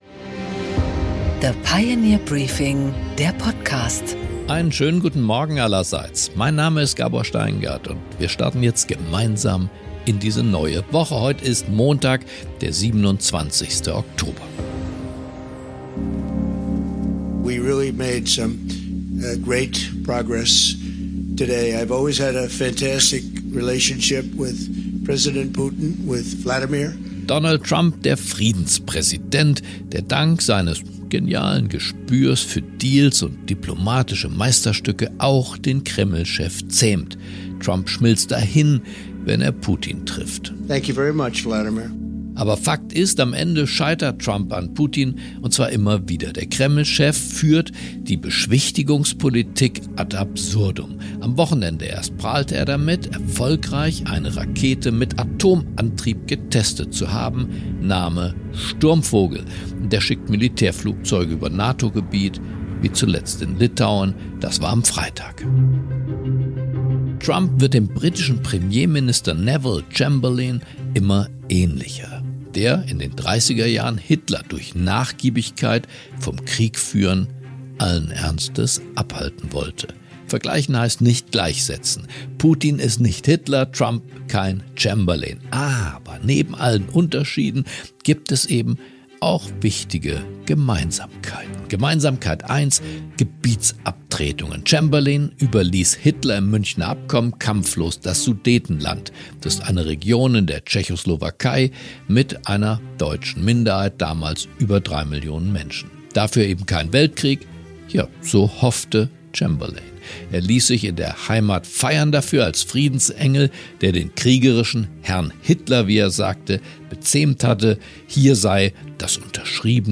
Gabor Steingart präsentiert das Pioneer Briefing.
Eine Analyse von Gabor Steingart.
Im Gespräch mit Gabor Steingart erklärt er, warum Deutschland in die längste Krise seiner Nachkriegsgeschichte schlittert, wie Bürokratie und Energiepreise Investitionen bremsen – und welchen Kurs die Regierung jetzt einschlagen müsste, um die Wirtschaft wieder flottzumachen.